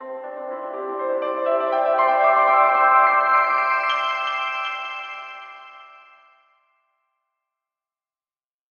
Звук для логотипной мелодии